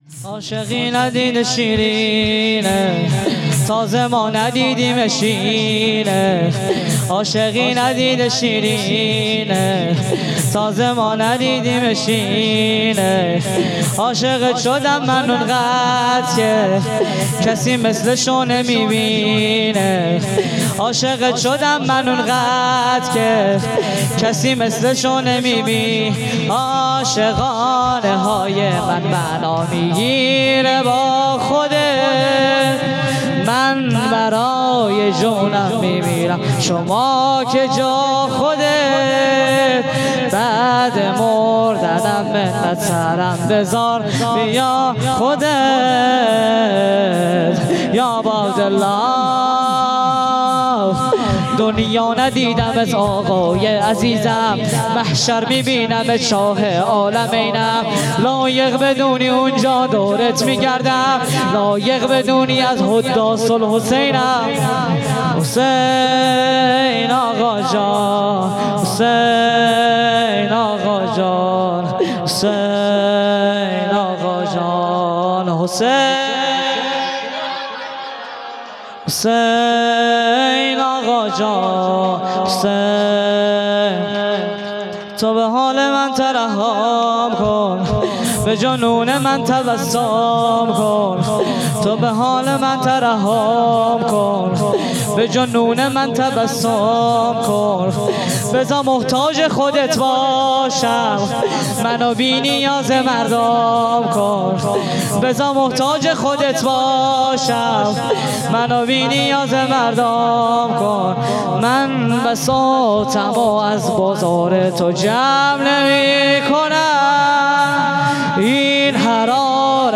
جلسه هفتگی 1404/2/1